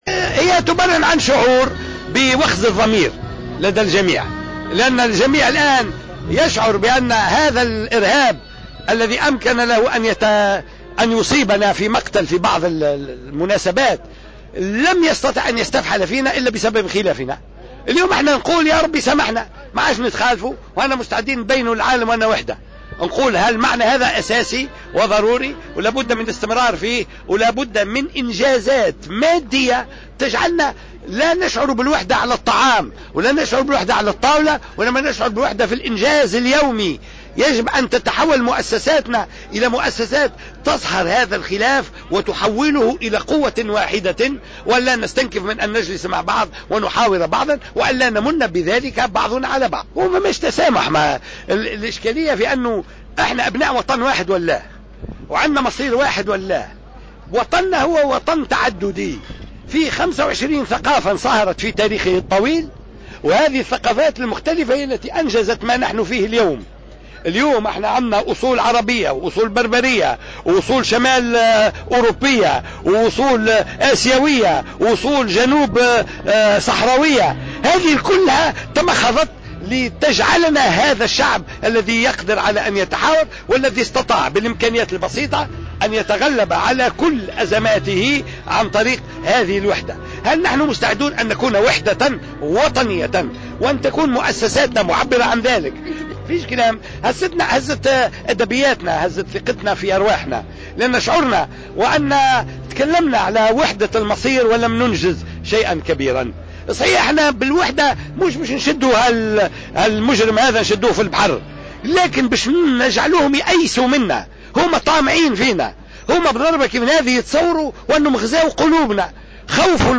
دعا عبد الفتاح موروعلى هامش الإفطار الجماعي الذي نظمته اليوم الجامعة التونسية للمطاعم السياحية بساحة القصبة إلى الوحدة الوطنية و تظافر الجهود من أجل القضاء على آفة الإرهاب.